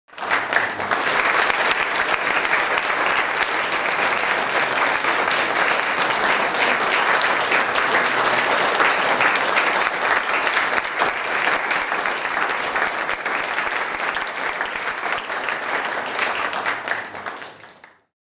Suoneria Applausi
Categoria Effetti Sonori